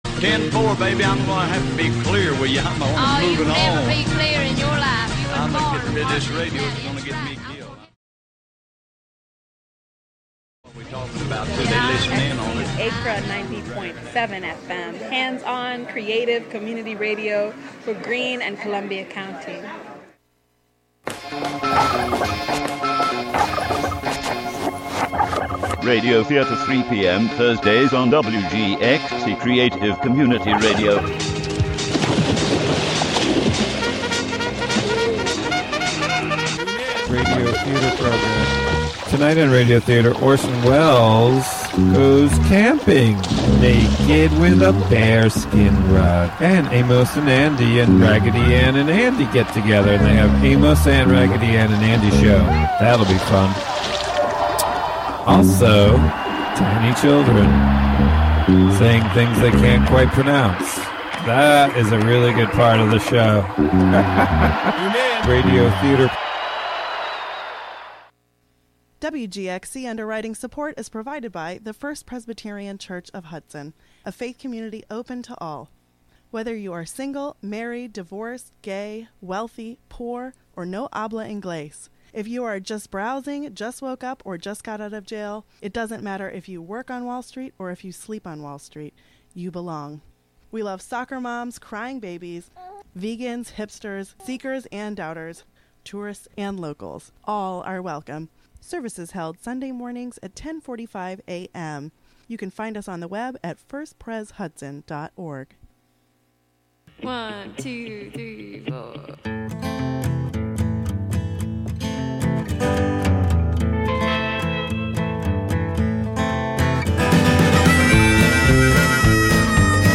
New or artifact, urban or rural – City Folk is a curated field guide for the humble, a study in dirt and bone. Broadcast live from the Hudson studio.